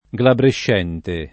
glabrescente [ g labrešš $ nte ]